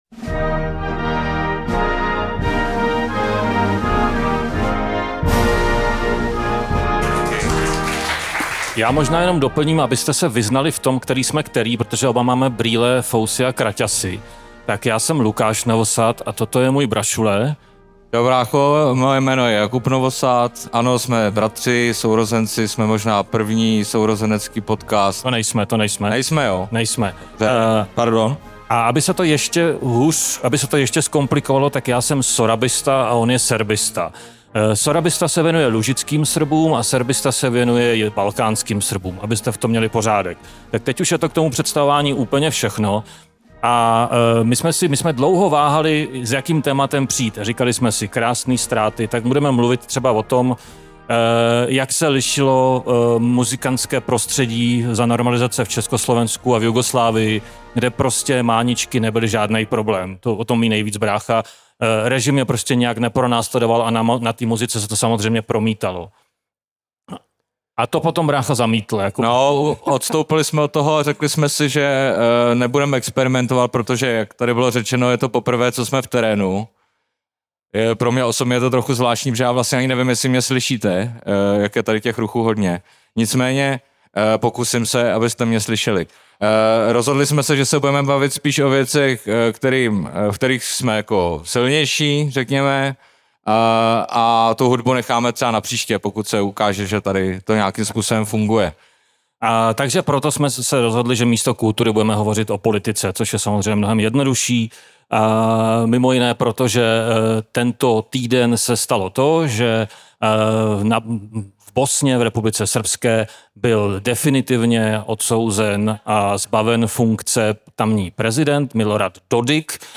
První veřejné vystoupení sourozeneckého podcastu Hej, Slované! se odehrálo v srpnu 2025 na festivalu Krásný ztráty ve Všeticích.